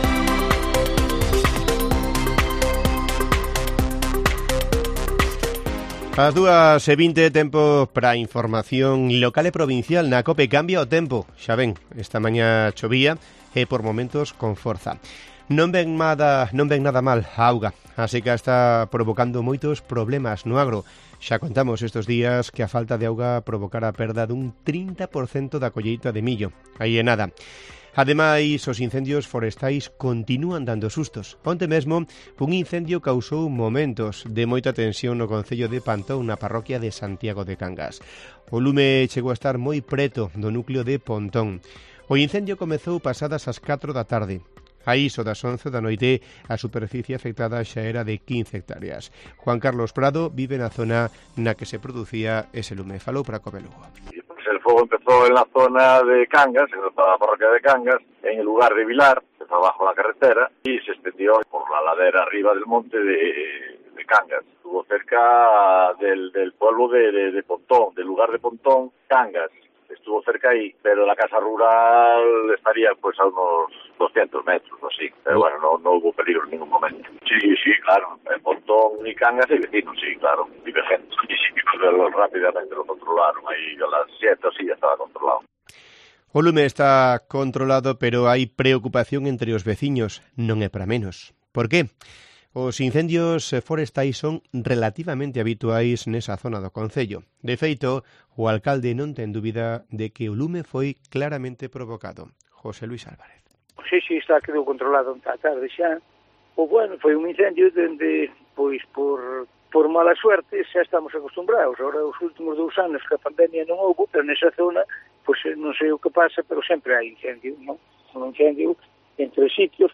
Informativo Mediodía de Cope Lugo. 05 de septiembre. 14:20 horas